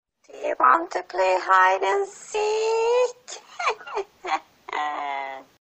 Звуки бабки Granny
Вы можете скачать её леденящий душу смех, скрип дверей, удары молотка и другие жуткие звуковые эффекты в высоком качестве.